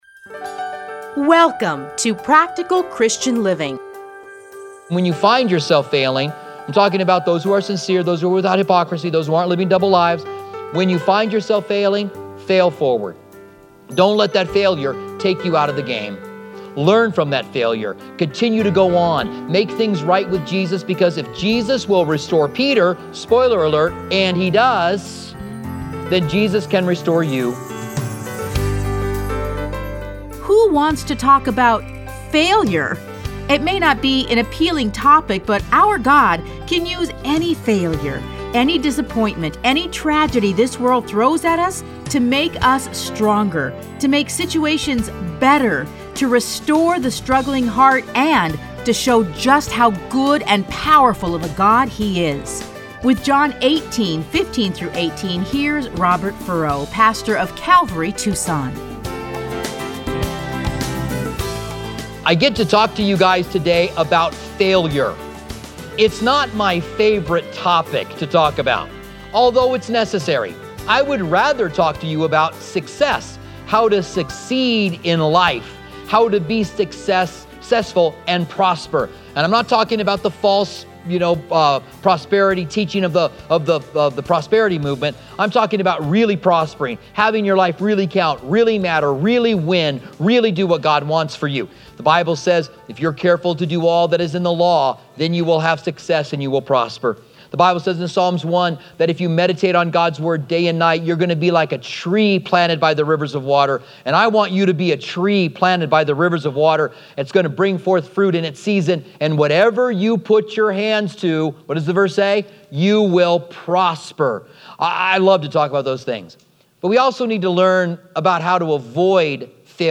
Listen to a teaching from John 18:15-27.